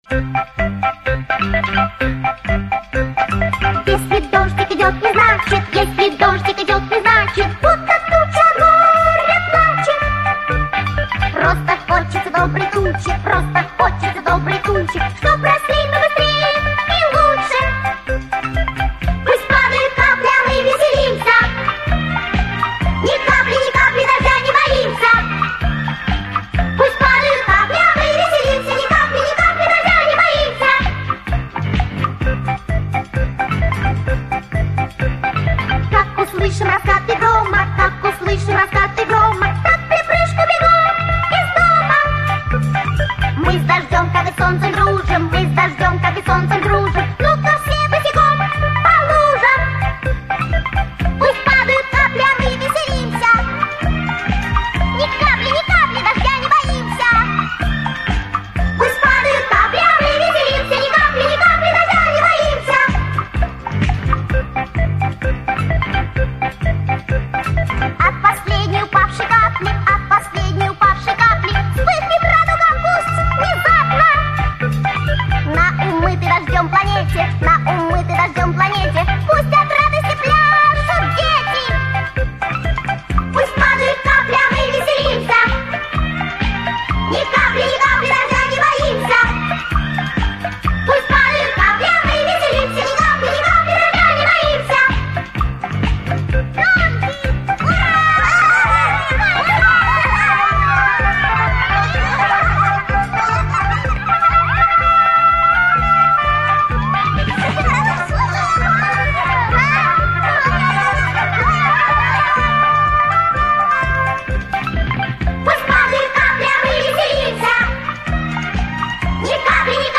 Песенки про осень